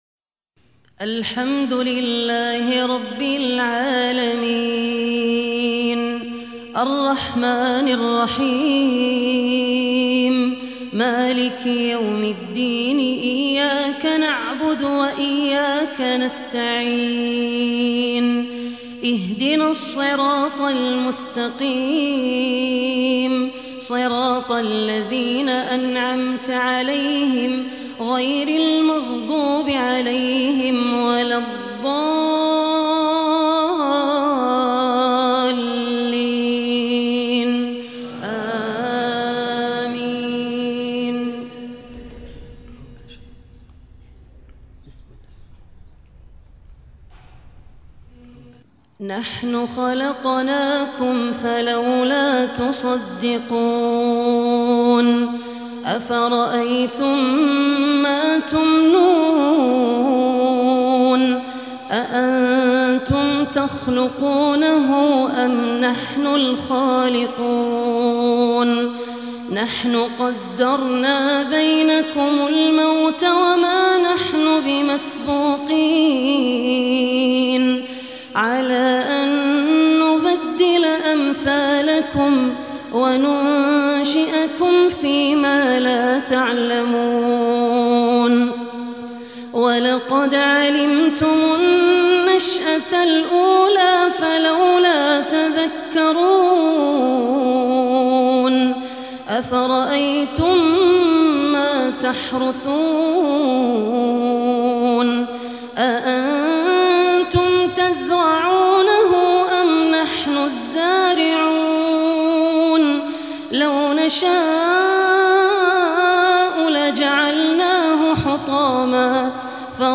Beautiful Quran Recitation